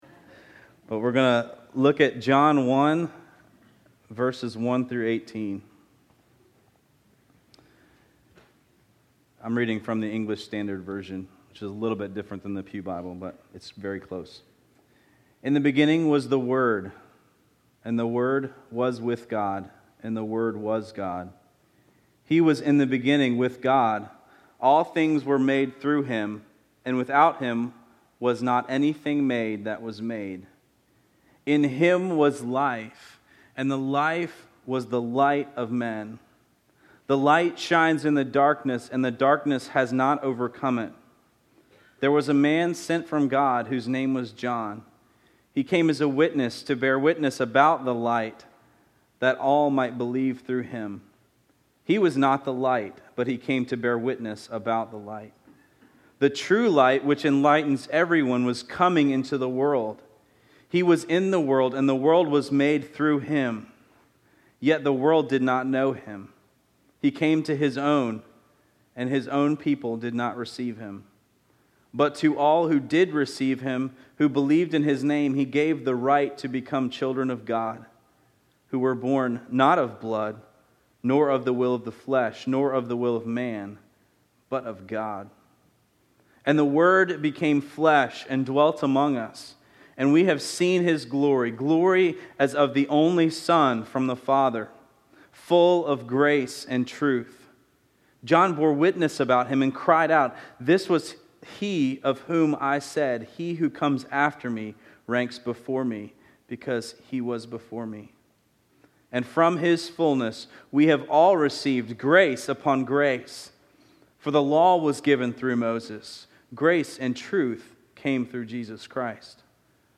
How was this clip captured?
In preparing for today’s service it came to my realization that I would only have about 10 minutes to preach.